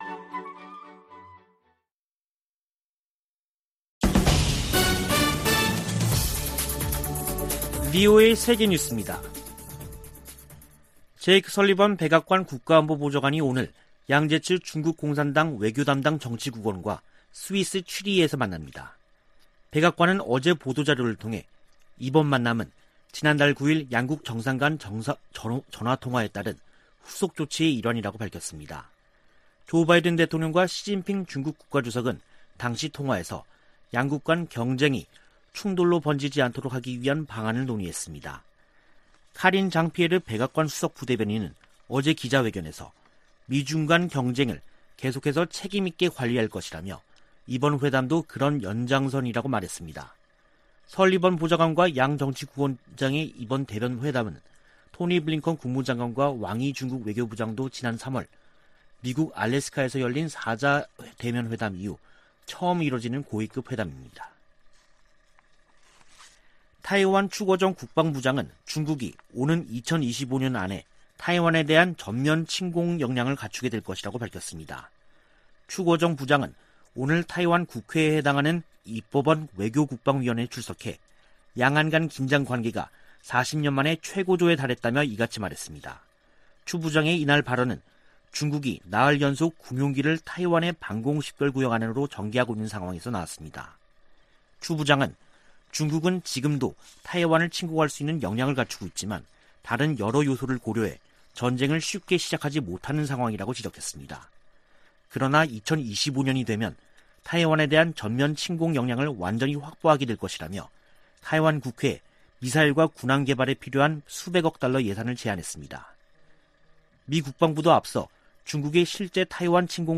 VOA 한국어 간판 뉴스 프로그램 '뉴스 투데이', 2021년 10월 6일 2부 방송입니다. 미 국무부는 유엔 안전보장이사회 전문가패널 중간보고서 발표와 관련, 북한의 계속되는 불법 활동에 우려를 나타냈습니다. 유럽연합과 덴마크 등이 유엔에서, 북한의 핵과 탄도미사일 프로그램이 역내 안보 위협이라고 지적했습니다.